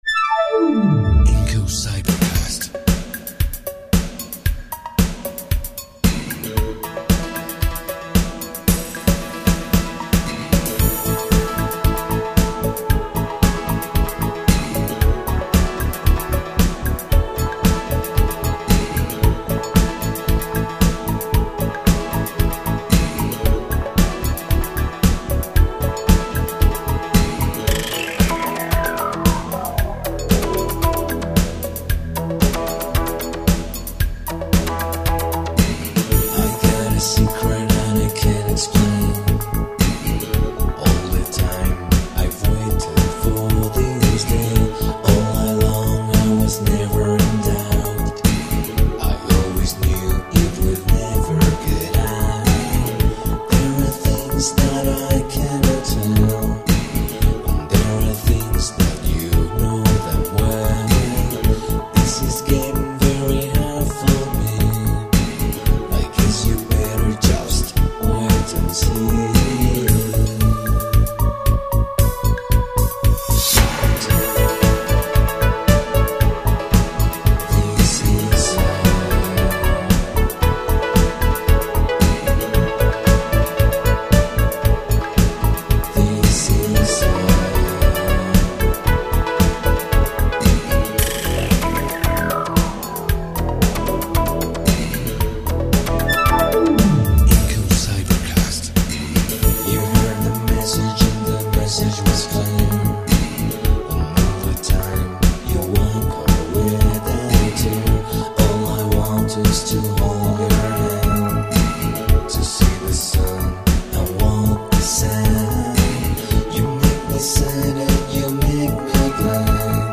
Voz & Coros